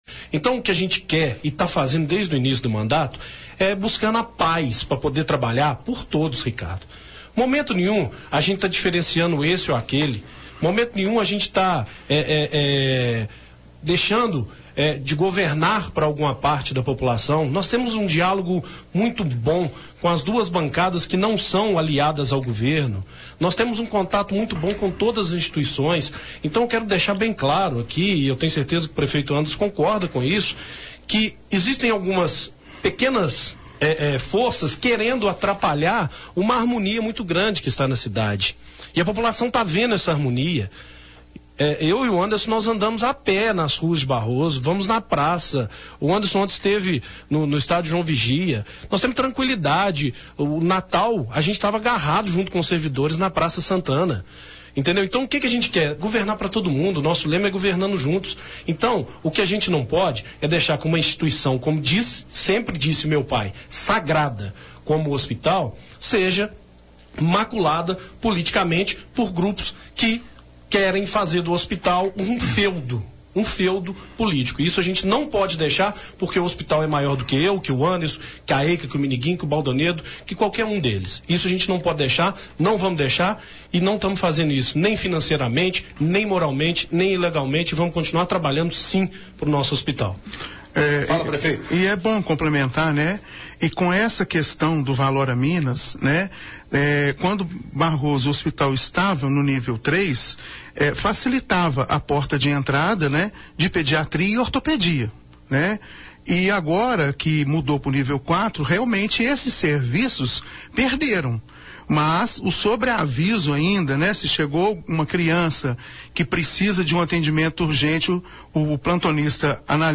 Em entrevista a Rádio Atrativa FM, de Dores de Campos, o Prefeito de Barroso Anderson de Paula (Democratas) e o Vice-prefeito Eduardo Pinto (Cidadania), falaram por cerca de uma hora sobre o primeiro ano de mandato na cidade.
ENTREVISTA-ANDERSON-E-EDUARDO.mp3